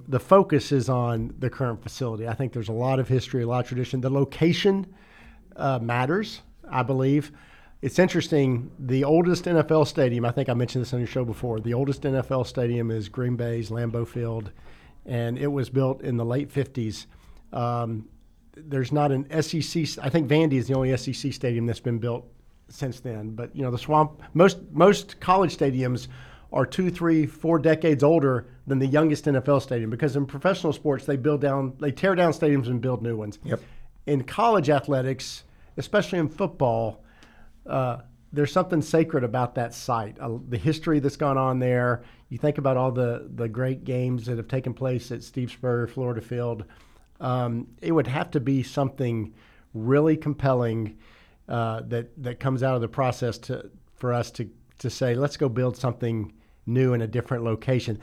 During the show, Gators fans could call in and offer their two cents about the project. Some people were worried about the prospect of less seats, others discussed ADA accessibility.